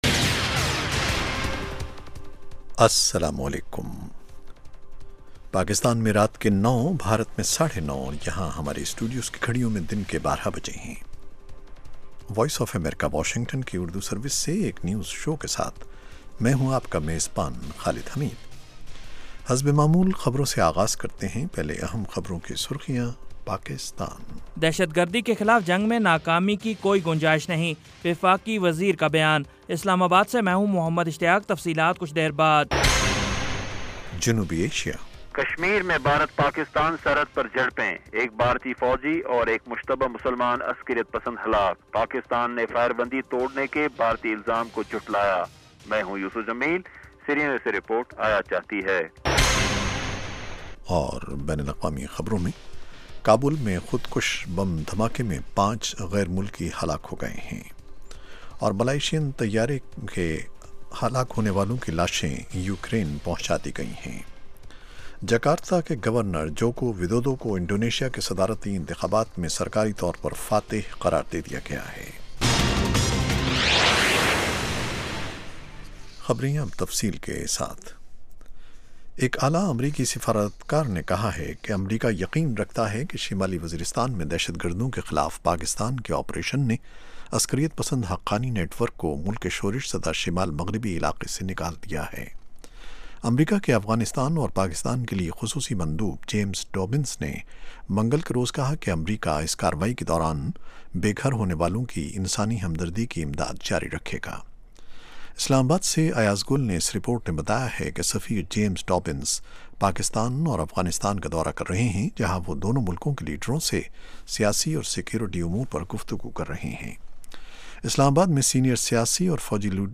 9:00PM اردو نیوز شو